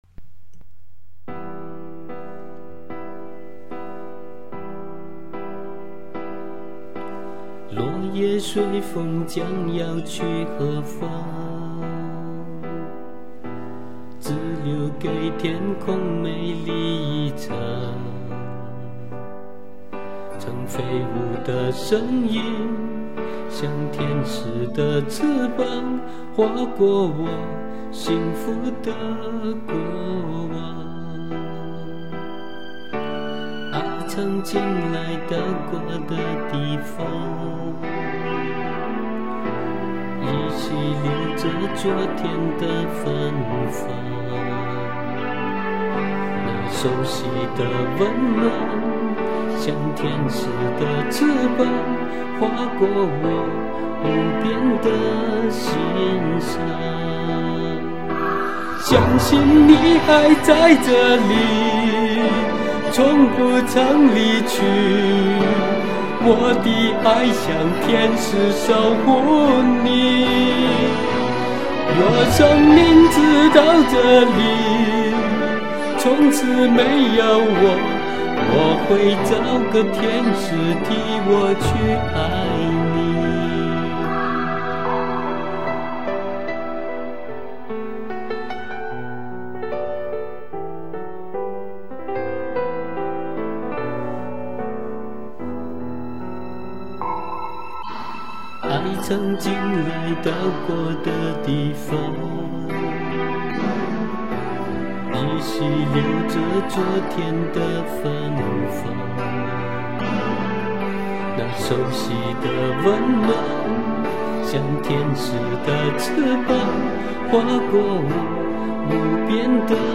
伴奏不太完美...凑合用了
想演一个温欣一点对父亲的想念...